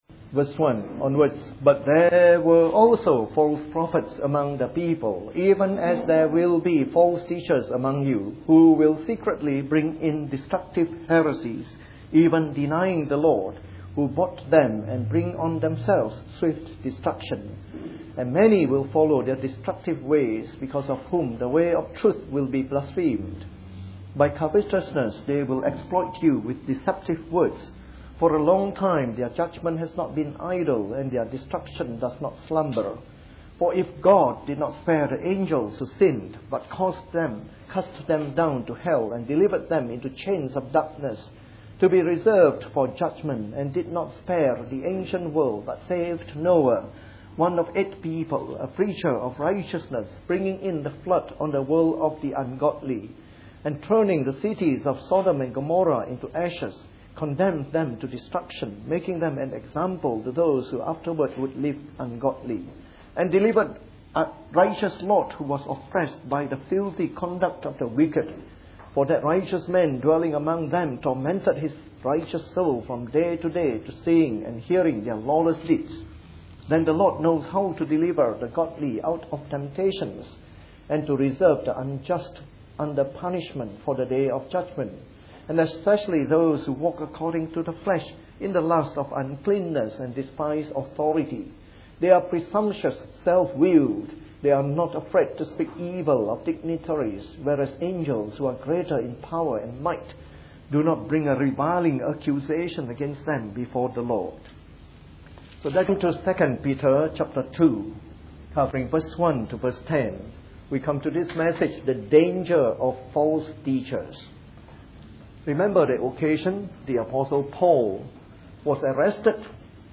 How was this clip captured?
Part of our series on “The Epistles of Peter” delivered in the Evening Service.